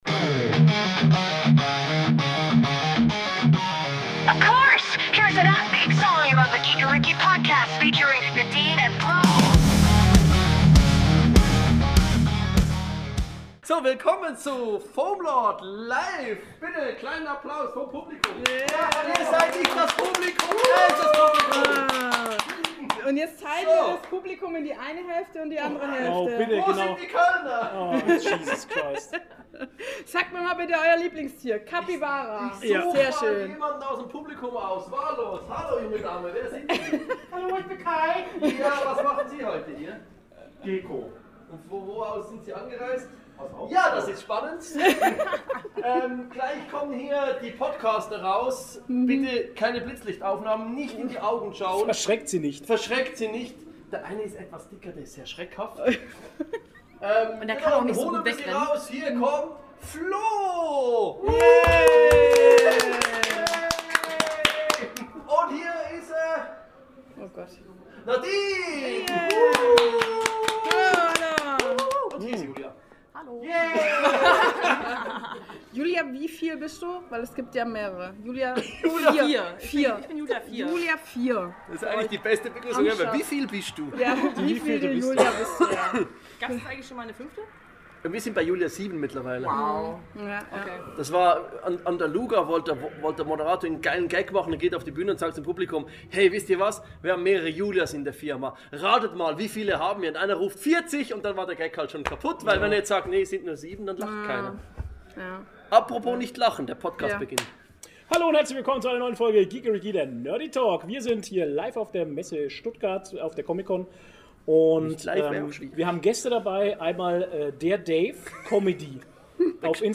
Diesmal melden wir uns live von der Comic Con Stuttgart!